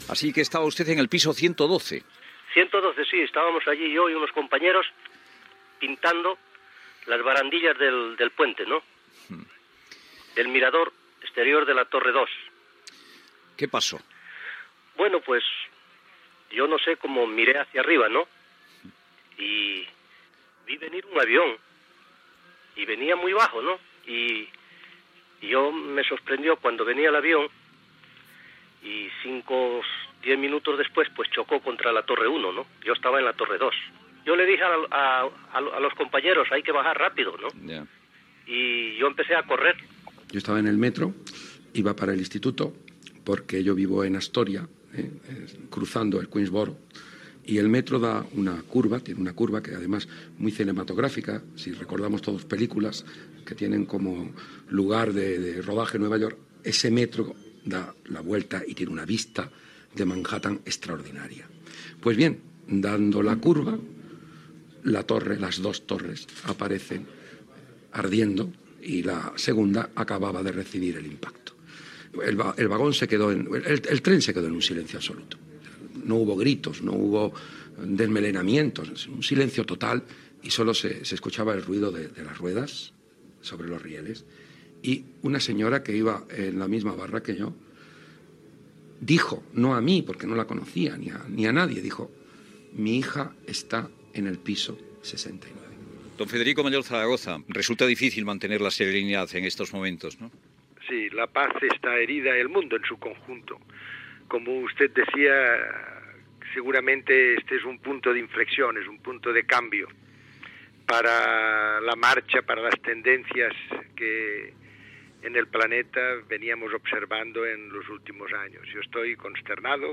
Testimonis de persones que van viure l'atemptat de l'11 de setembre a Nova York
Info-entreteniment
FM